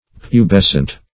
Pubescent \Pu*bes"cent\ (p[-u]"b[e^]s"sent), a. [L. pubescens,